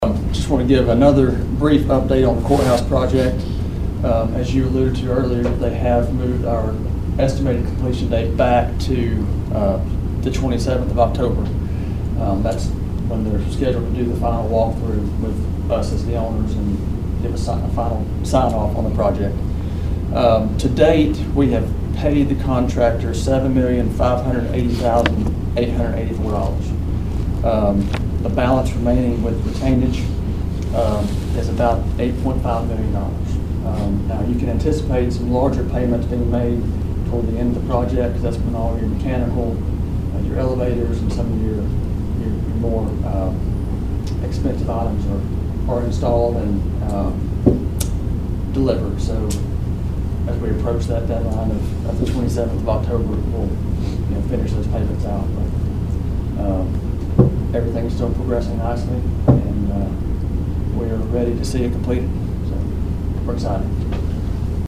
During the Cherokee County Commission meeting on Monday, April 28, the commission was updated on the status of the new Cherokee County Courthouse. County Administrator Daniel Steele had these comments on the issue: